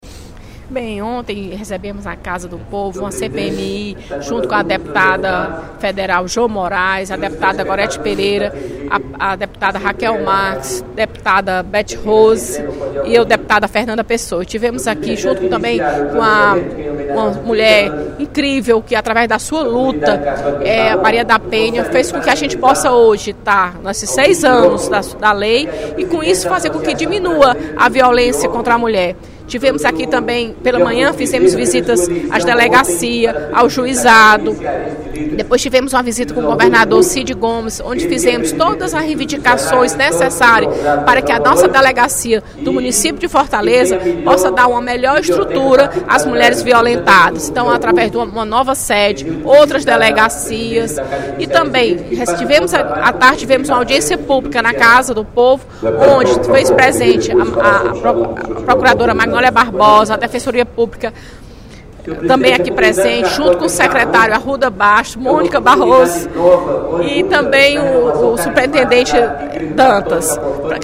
Durante o primeiro expediente da sessão plenária desta terça-feira (11/12), a deputada Fernanda Pessoa (PR) registrou a vinda da Comissão Parlamentar Mista de Inquérito (CPMI) da Violência contra Mulher à Assembleia, na última segunda-feira, para discutir o tema em audiência pública.